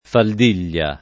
[ fald & l’l’a ]